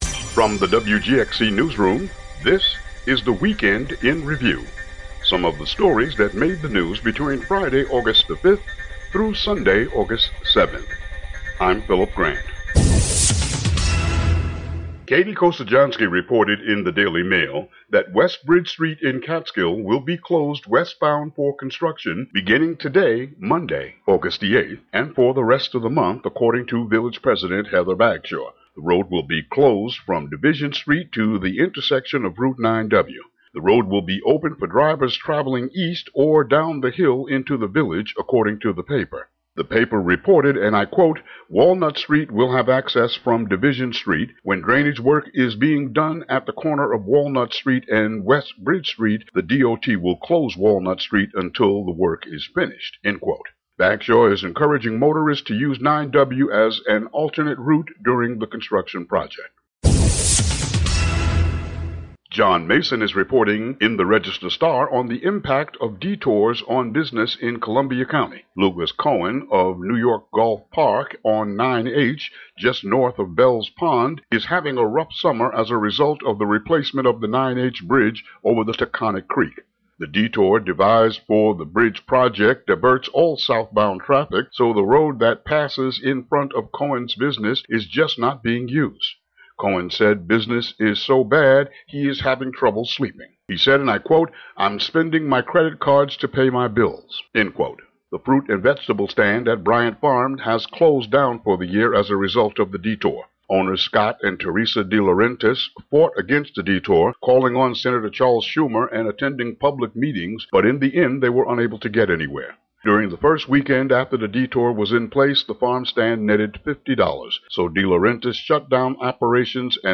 Regional news for Hudson Valley and Capitol Region.